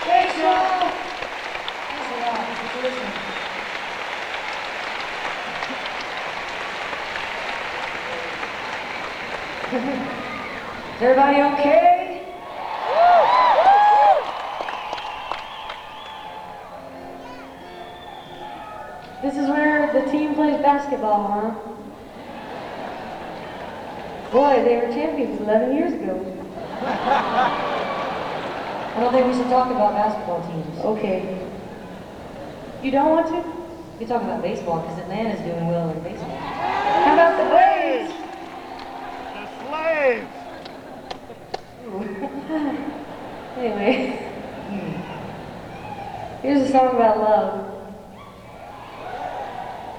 (acoustic duo)
05. talking with the crowd (0:50)